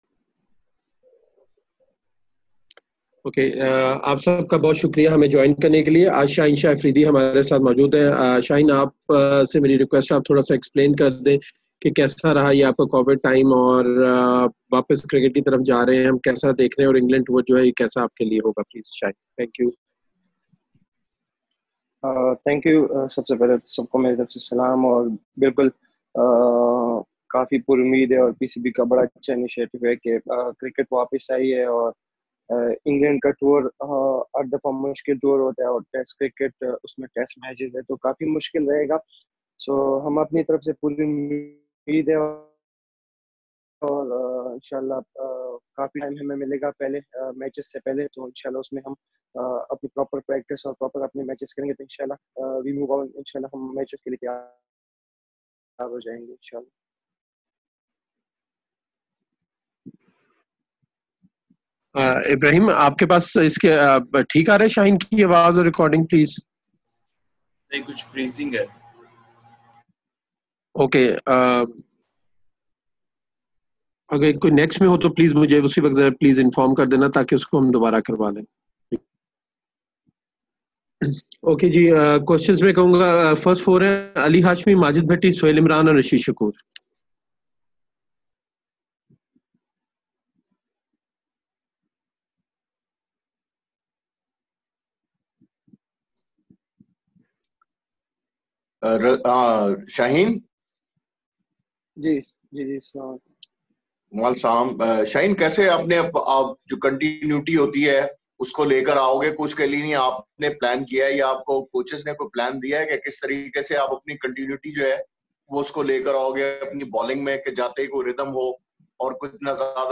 Shaheen Shah Afridi interacted with the local media via videoconference call today.